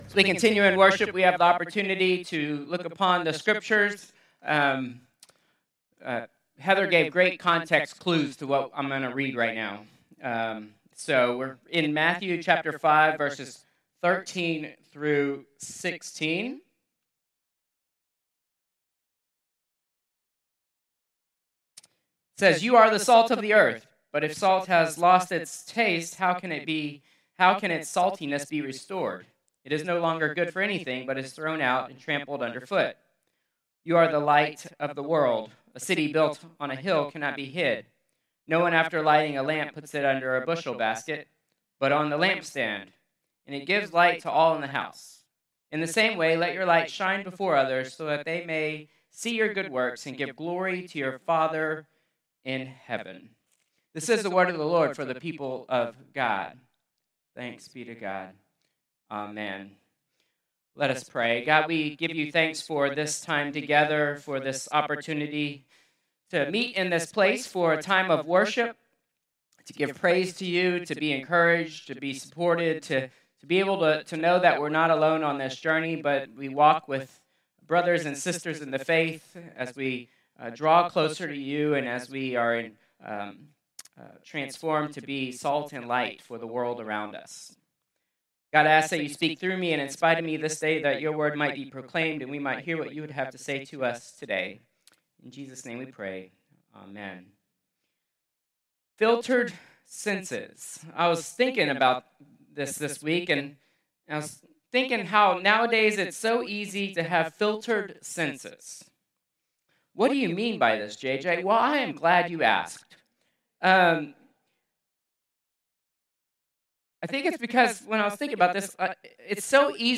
Contemporary Worship 11_16_2025